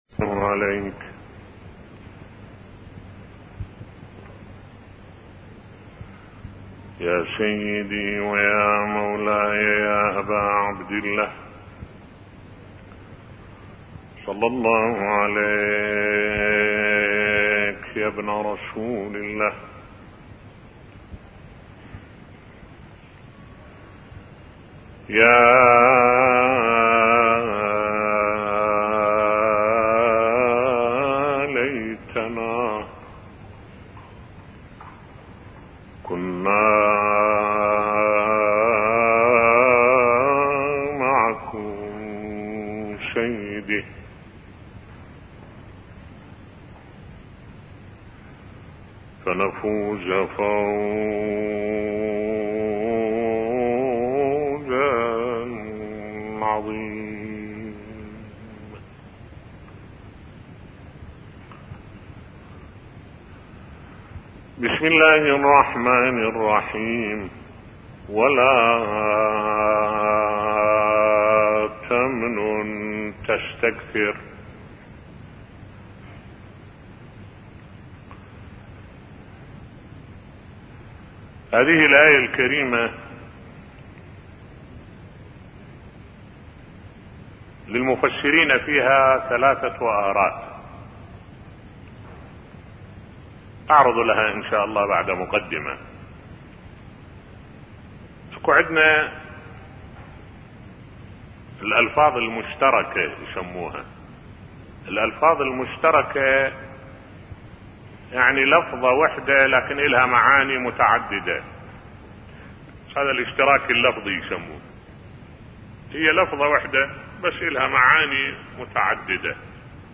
ملف صوتی ولا تمنن تستكثر بصوت الشيخ الدكتور أحمد الوائلي